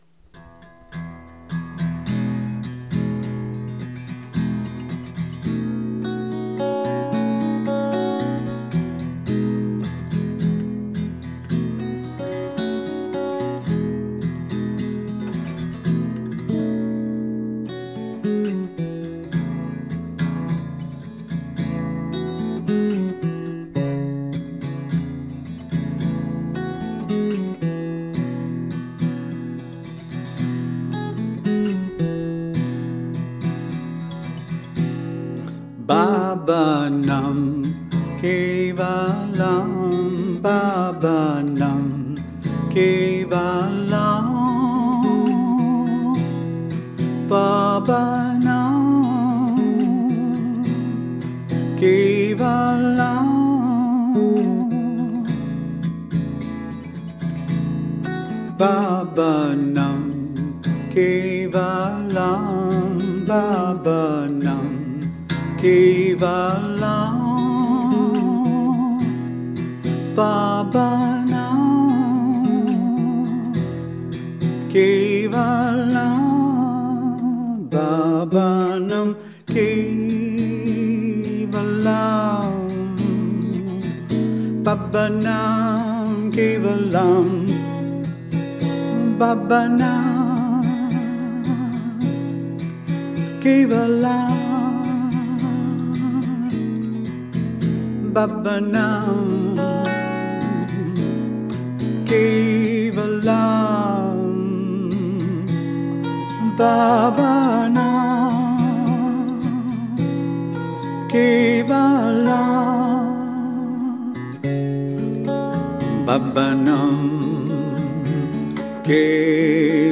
guitar, voice